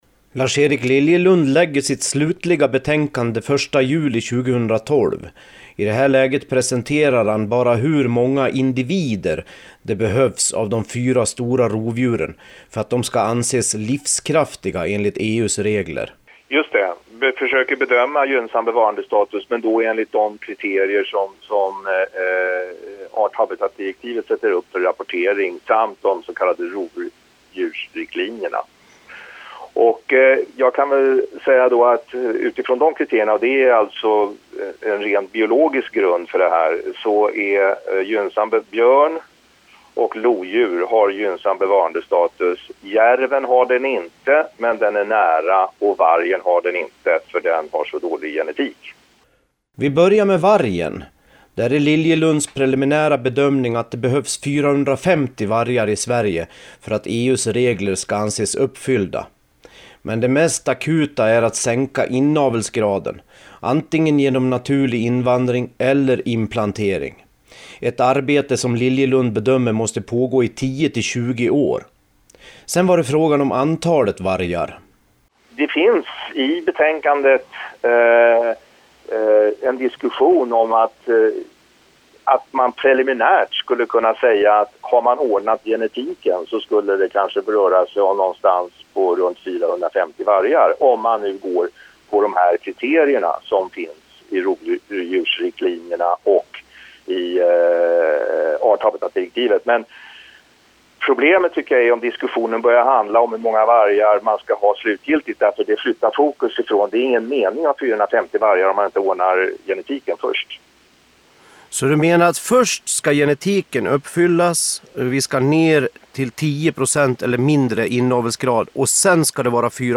Lars-Erik Liljelund via telefon
Hör Liljelund berätta om sitt utredningsresultat i Jakt & Jägares webbradio.